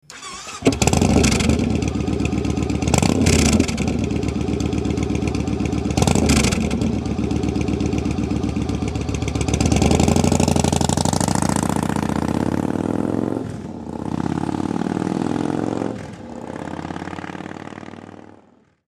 Starten